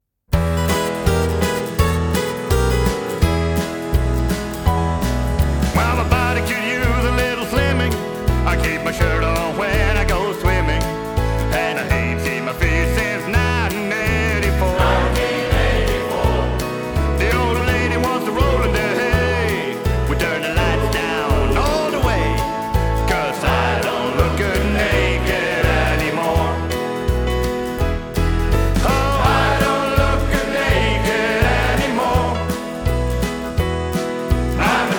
Акустические струны и тёплый вокал
Folk
Жанр: Фолк